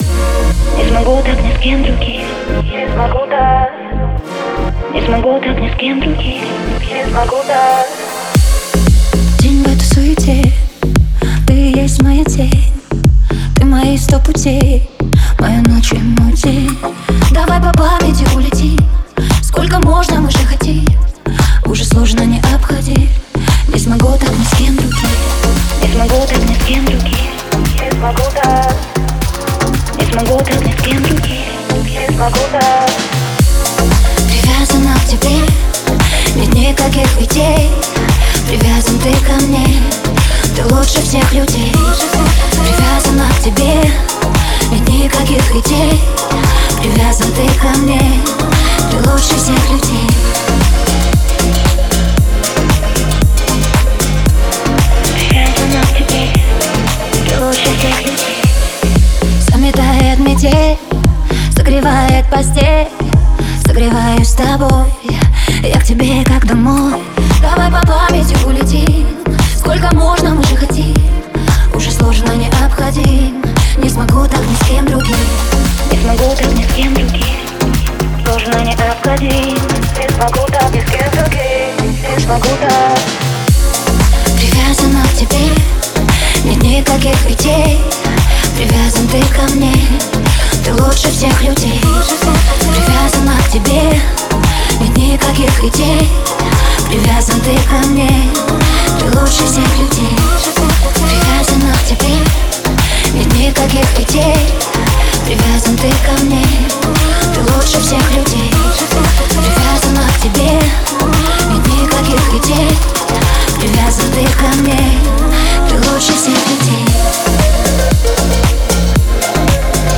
динамичные биты и современные синтезаторы